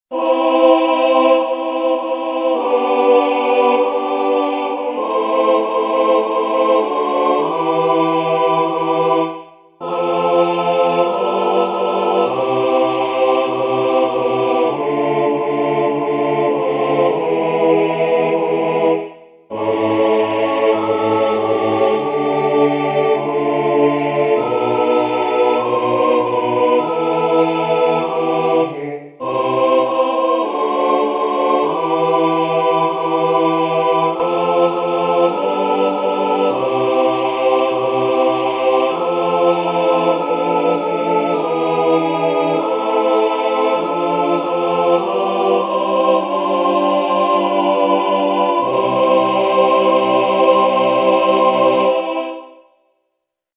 avec le public en 2005
Par le chœur d'hommes original